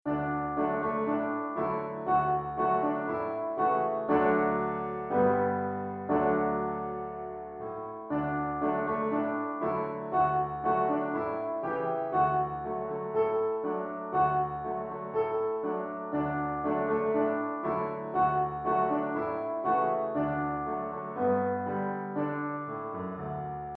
Piano Solo
Downloadable Instrumental Track